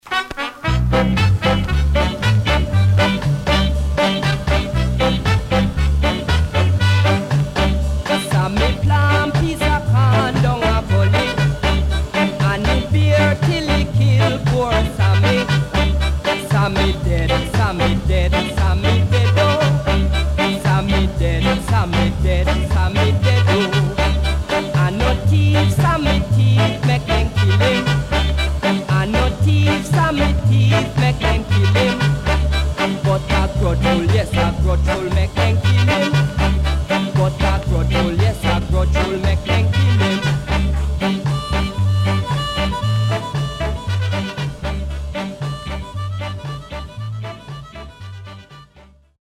SIDE A:出だし傷によりノイズ入ります。全体にチリノイズ入ります。